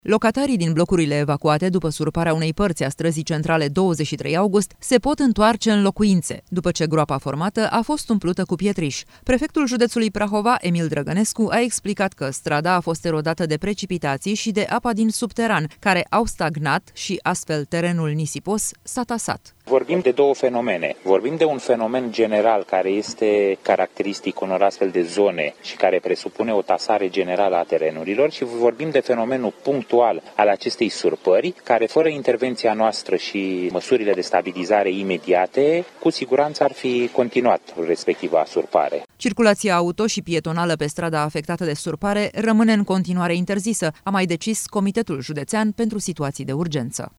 „Vorbim de două fenomene. Vorbim de un fenomen general, care este caracteristic unor astfel de zone și care presupune o tasare generală a terenurilor și vorbim de fenomenul punctual al acestei surpări, care, fără intervenția noastră și măsurile de stabilizare imediate, cu siguranță ar fi continuat respectiva surpare”, a precizat prefectul județului Prahova, Emil Drăgănescu.